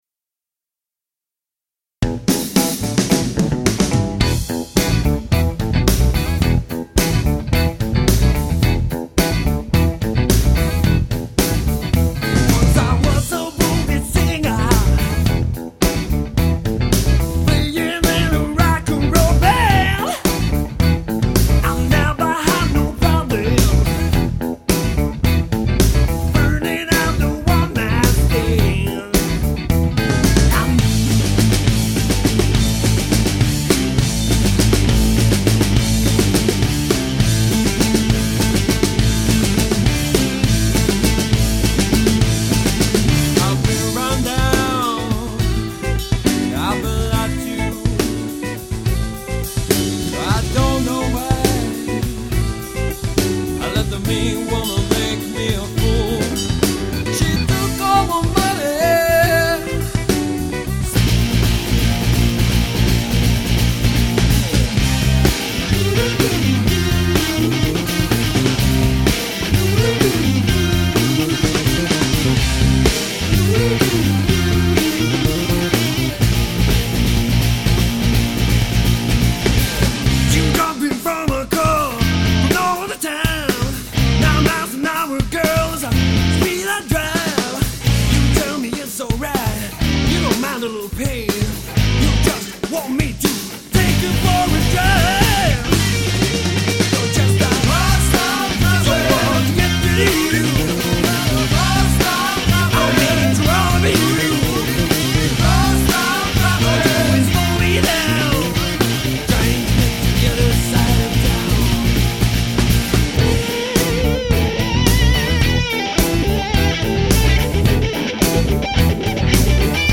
laulu
kitara
kitara, taustalaulu
basso, taustalaulu
rummut, taustalaulu